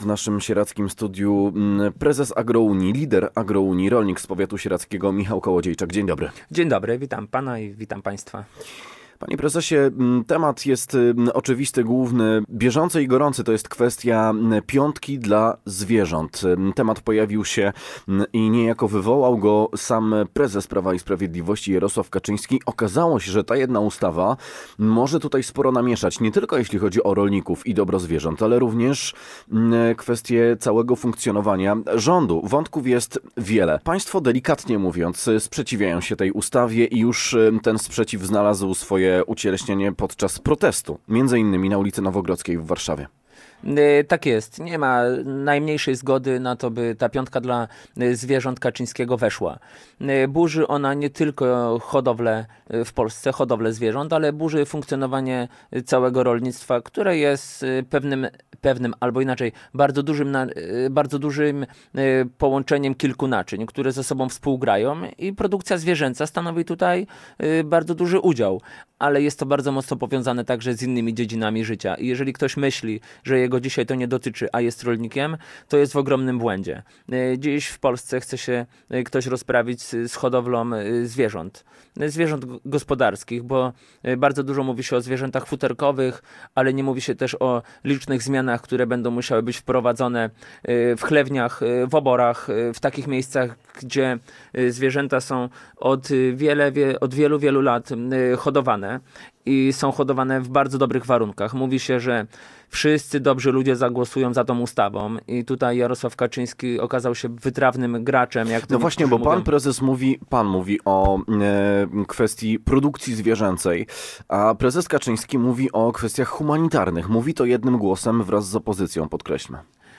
Rozmowa Dnia – Michał Kołodziejczak